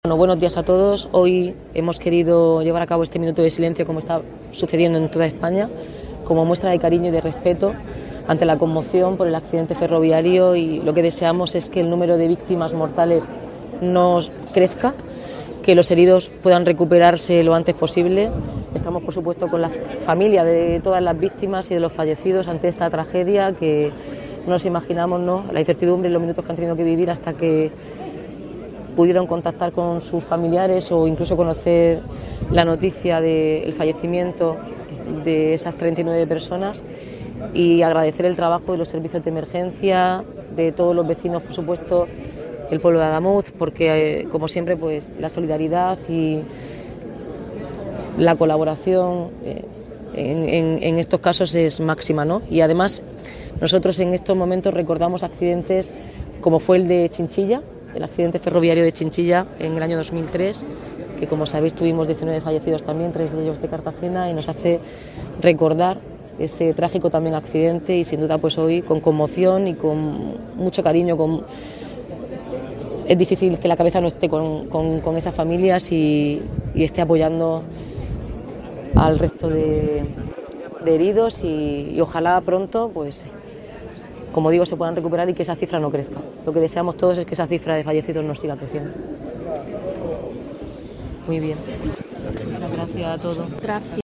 El minuto de silencio tuvo lugar a las 12 del mediodía en la plaza del Ayuntamiento con la participación de integrantes de la corporación municipal, personal del Ayuntamiento y autoridades civiles.